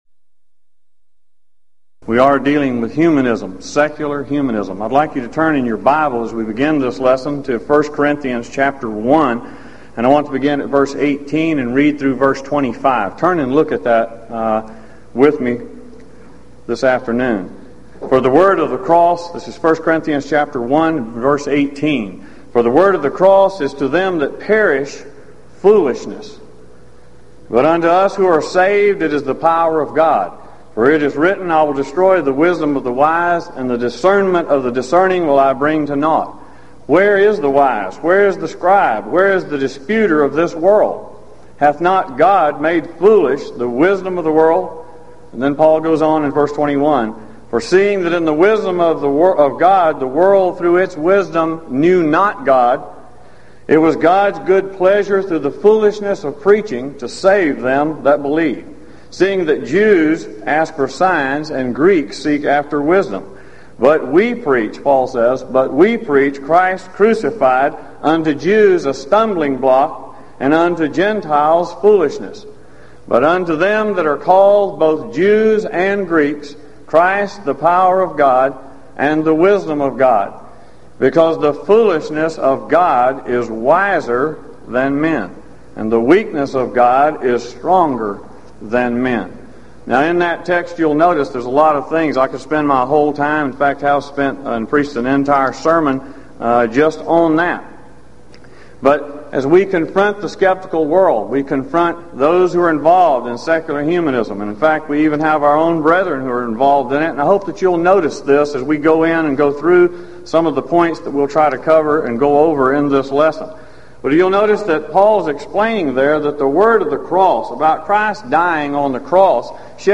Event: 1996 Gulf Coast Lectures
If you would like to order audio or video copies of this lecture, please contact our office and reference asset: 1996GulfCoast16